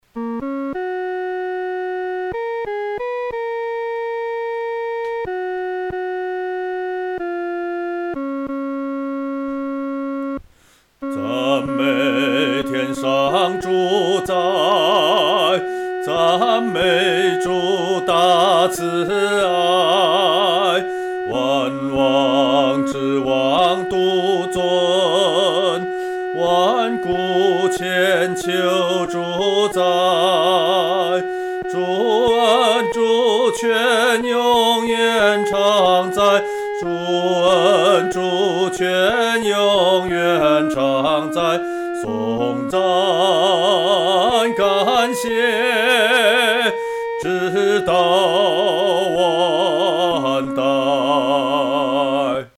独唱（第二声）
曲调欢快、明朗